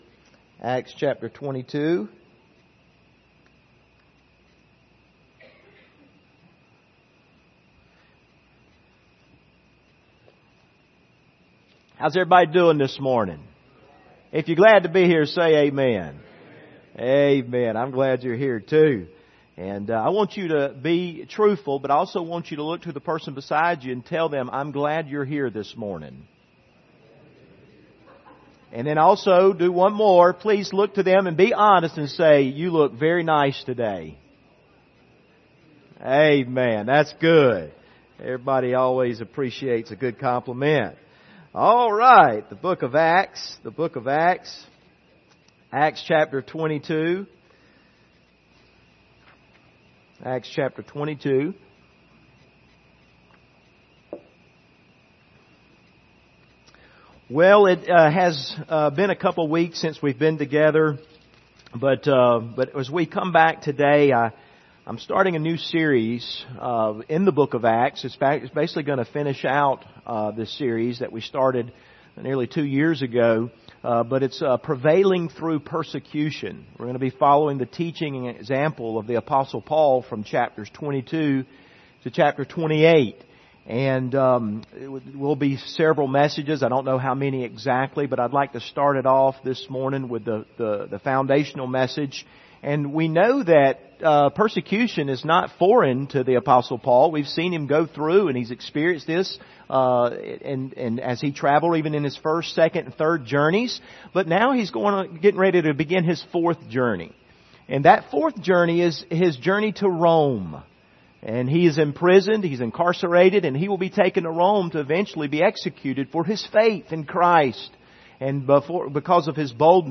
Service Type: Sunday Morning Topics: persecution